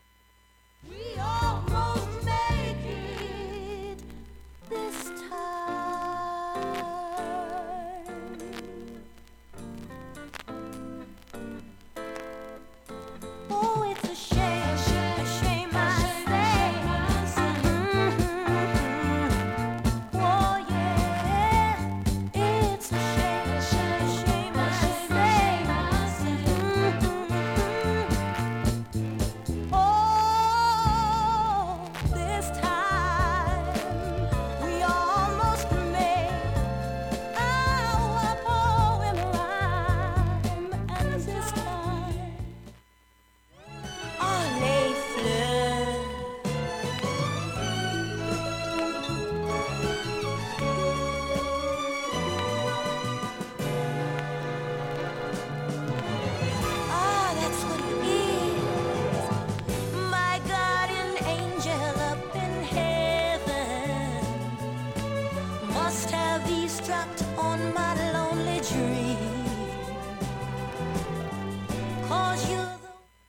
わずかな周回シャリ音が45秒間出ます。
B-3中盤にかすかなプツが１４回出ます。
サザンレディスイートソウル名盤